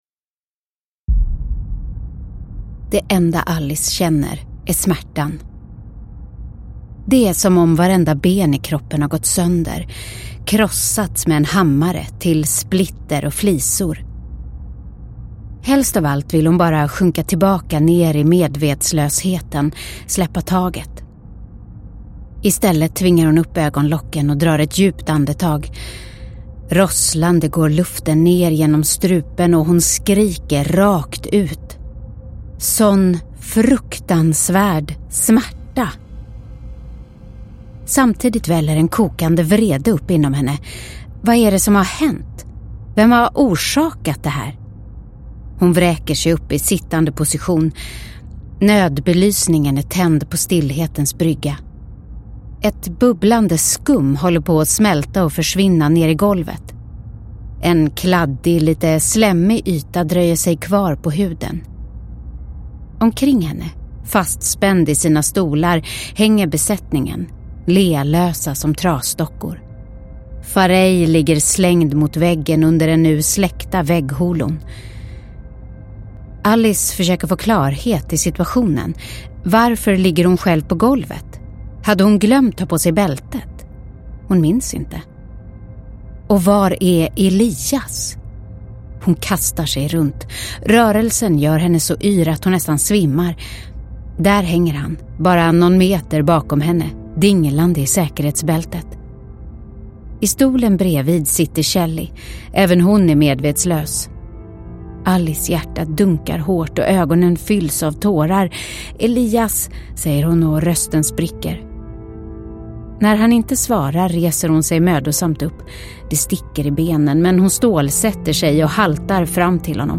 Gravplaneten – Ljudbok – Laddas ner